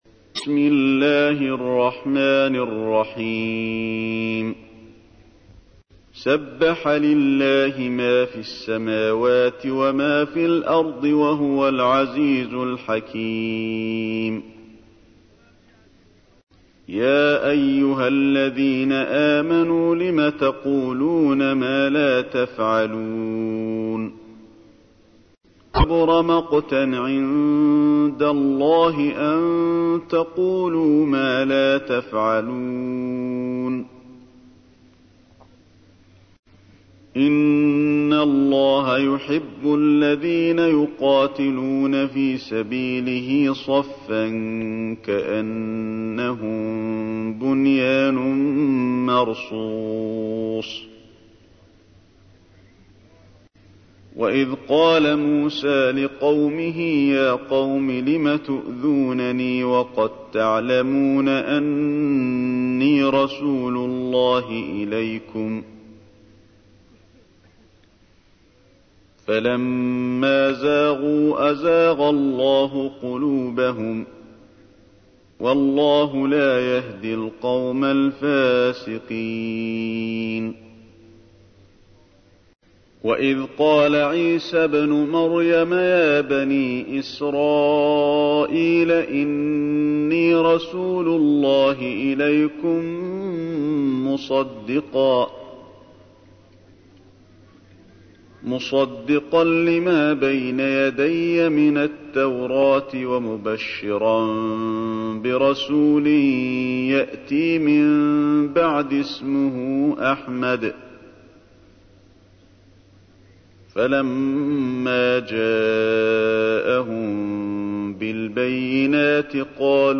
تحميل : 61. سورة الصف / القارئ علي الحذيفي / القرآن الكريم / موقع يا حسين